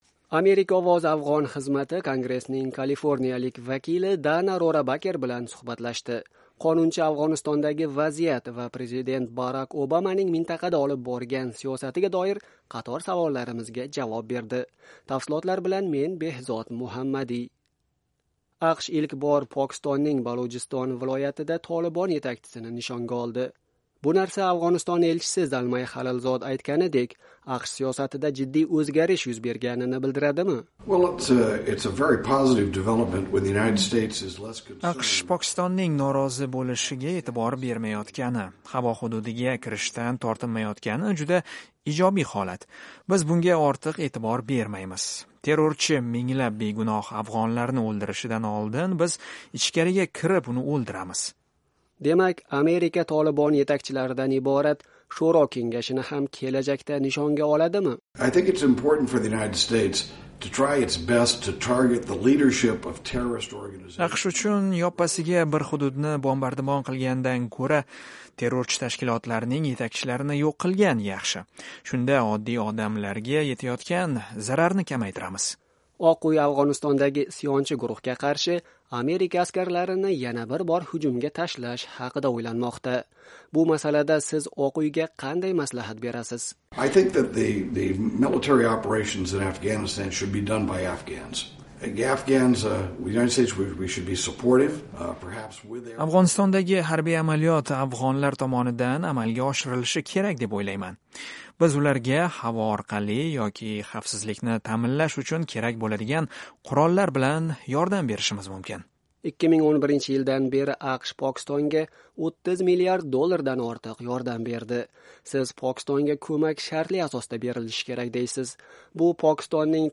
Kongressmen Dana Rorabaker bilan suhbat
Afg'onistondagi vaziyat va Prezident Barak Obamaning mintaqada olib borgan siyosati haqida Kongress a'zolari qanday fikrda? "Amerika Ovozi" Afg'on xizmati Kaliforniya shtatidan saylangan Dana Rorabaker bilan suhbatlashdi.